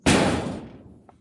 金属 " 小型金属刮削04
描述：金属隆隆声，撞击声和刮擦声。
标签： 光泽 铁匠 金属 工业 钢铁 隆隆声 指甲 命中 打击乐器 金属 刮去 工厂 冲击 锁定 工业
声道立体声